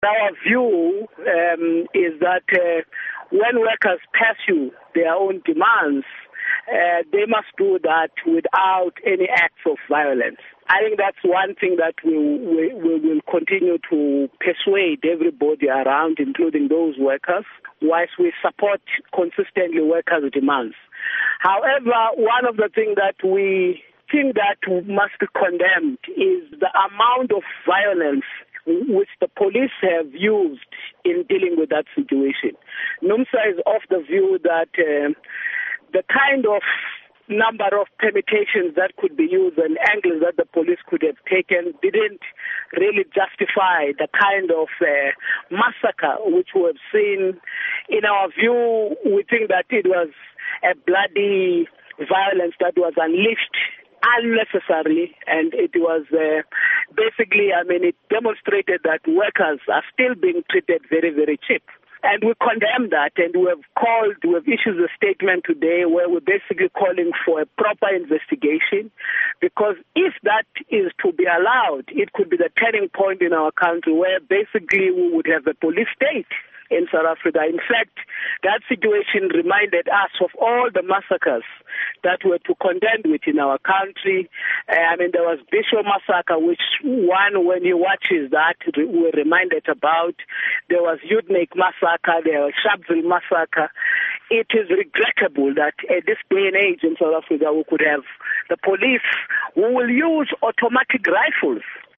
Interview With Irvin Jim